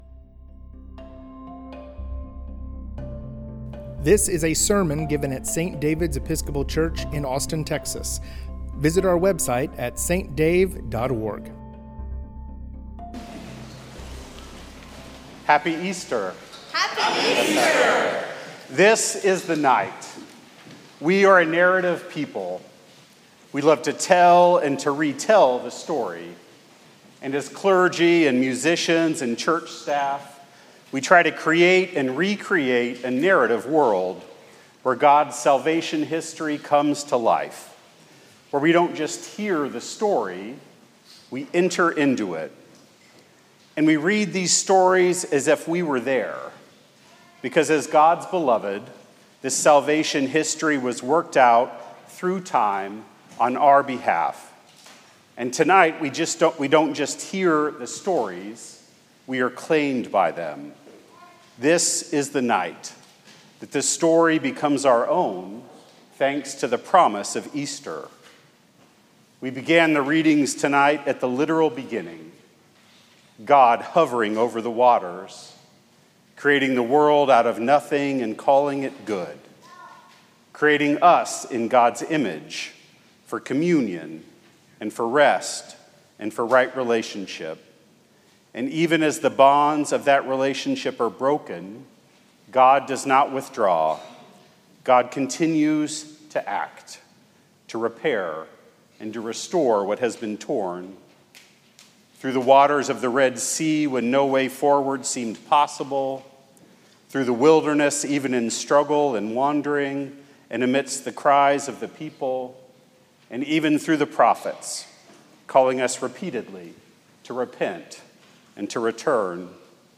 Sermons
sermon from the Great Vigil of Easter.